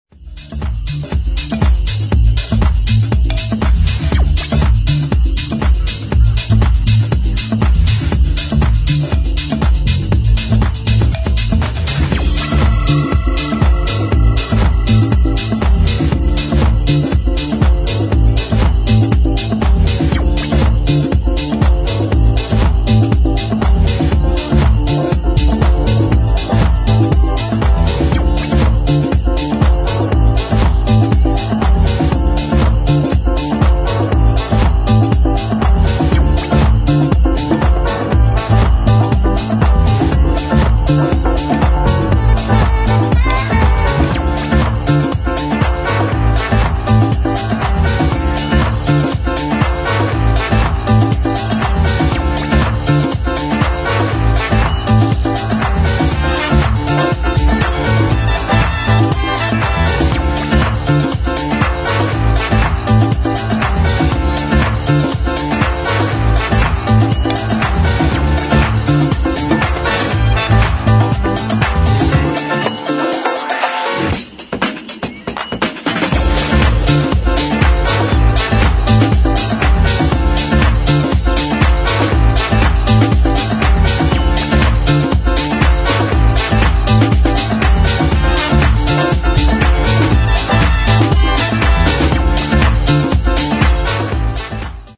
[ DISCO / EDIT ]
夏向けピアノ・メロディーとリズミカルなワークアウトが組み合わされた